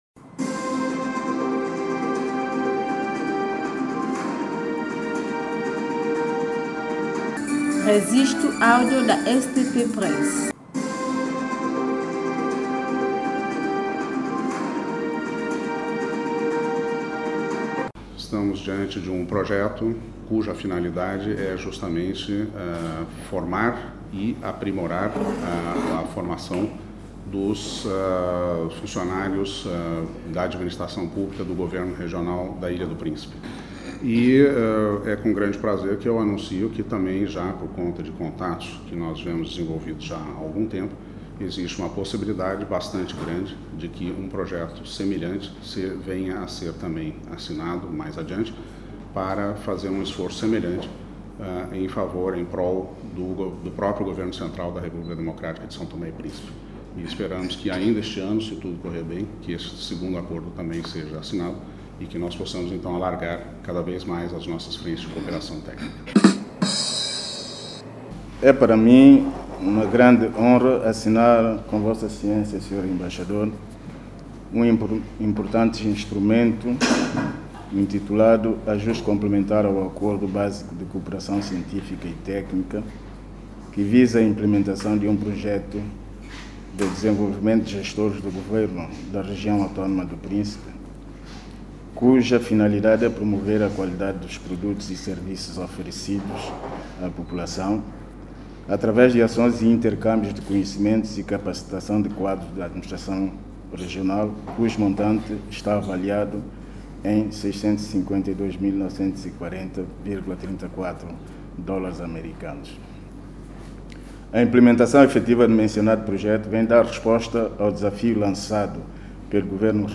Embaixador de Brasil e o Ministro dos Negócios Estrangeiros